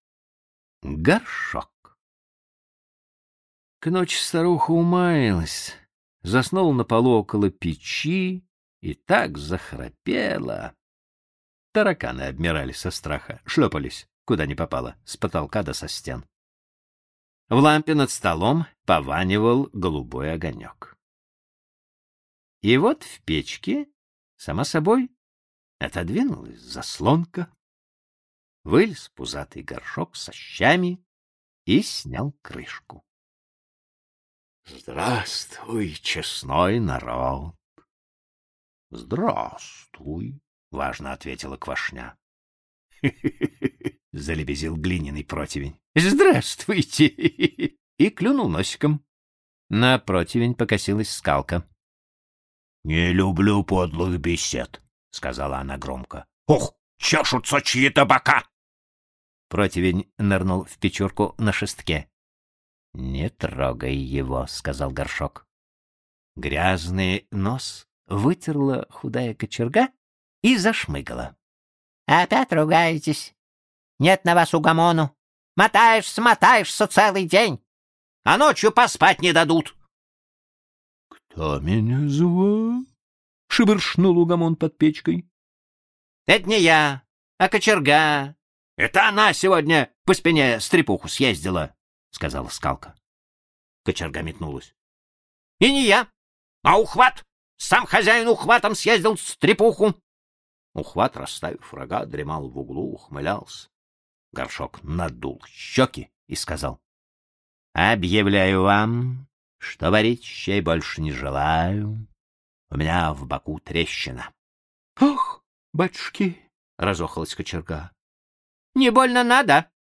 Горшок - аудиосказка Алексея Толстого - слушать онлайн | Мишкины книжки